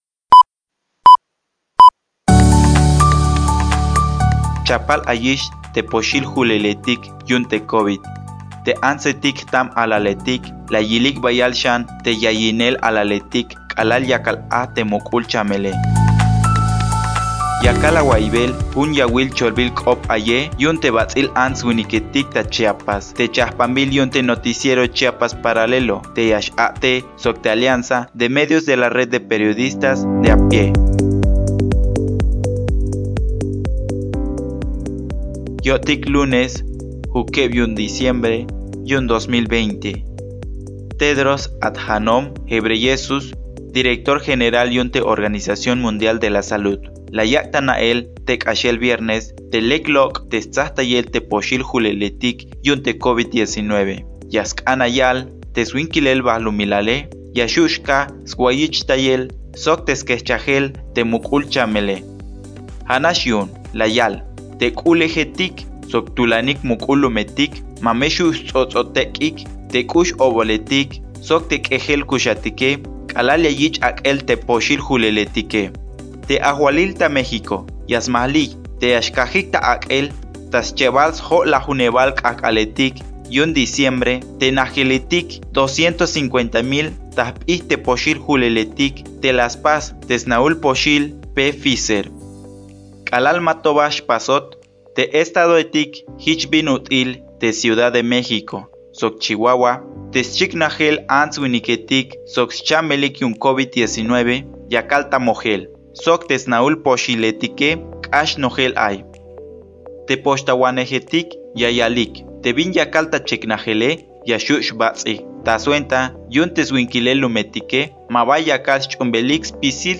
Yakal awaiybel jun yawil cholbil k’op ayej yu’un te bats’il ants winiketik ta Chiapas, te chajpanbil yu’un te Noticiero Chiapas Paralelo, te ya x-a’tej sok te Alianza de Medios de la Red de Periodistas de a Pie.